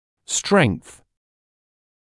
[streŋθ][стрэнс]сила; прочность; стабильность; сильная сторона, преимущество